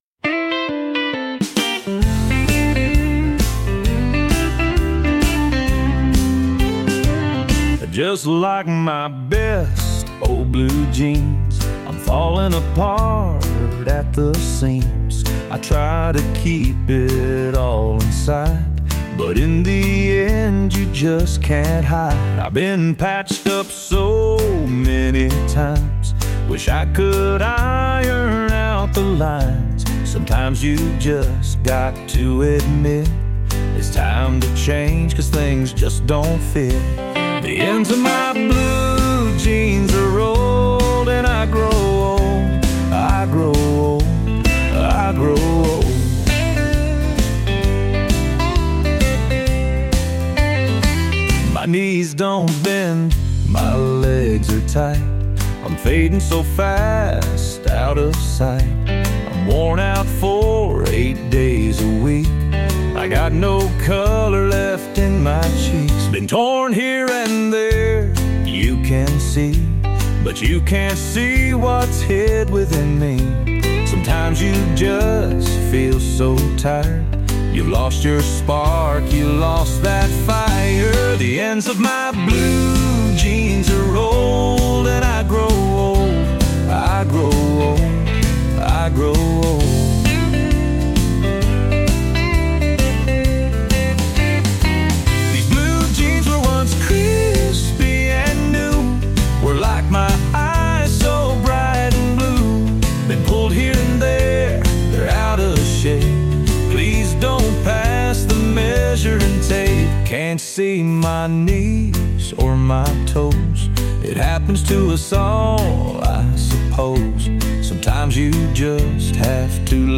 heartfelt country song